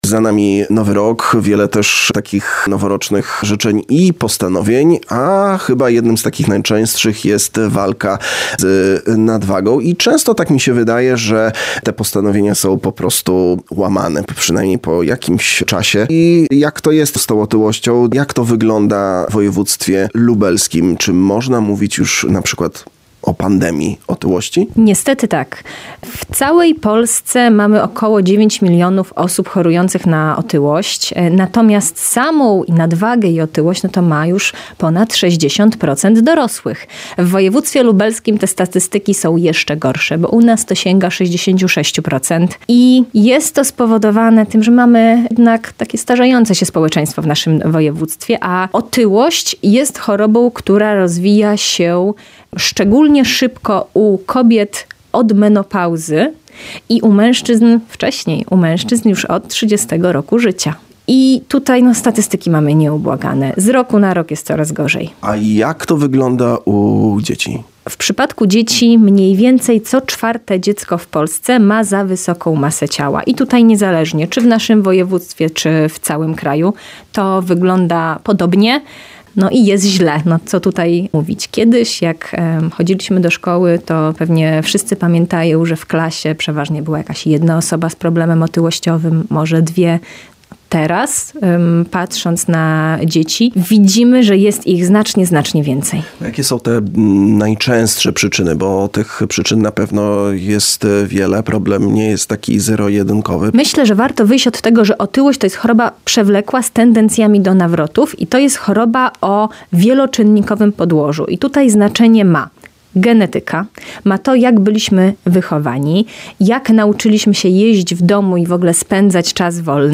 lekarzem specjalistą od leczenia otyłości rozmawiał